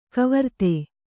♪ kavarte